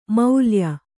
♪ maulya